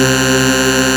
Single Cycle Unison Wave{7voice + sub}